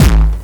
• Distorted Crispy Analog Techno Kick.wav
Distorted_Crispy_Analog_Techno_Kick_oWA.wav